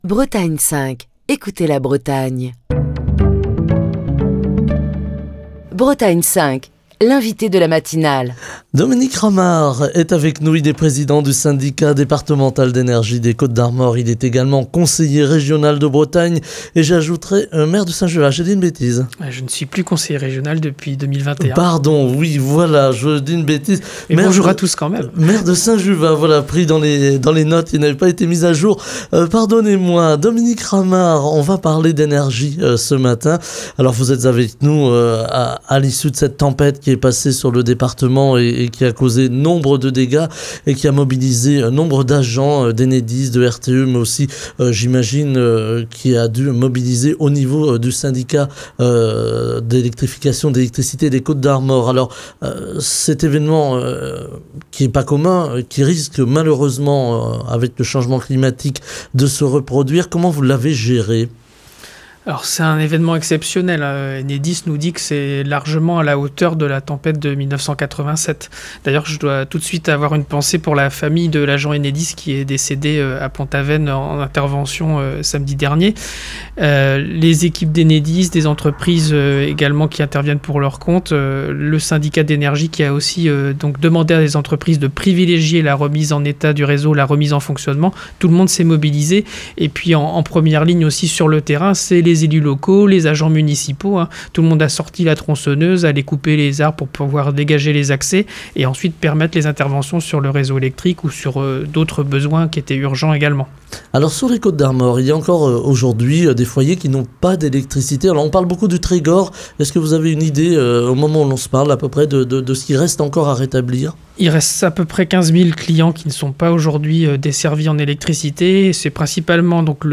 Dominique Ramard, président du Syndicat Départemental d’Énergie des Côtes-d'Armor et maire de Saint-Juvat fait le point sur la situation au micro de Bretagne 5 Matin.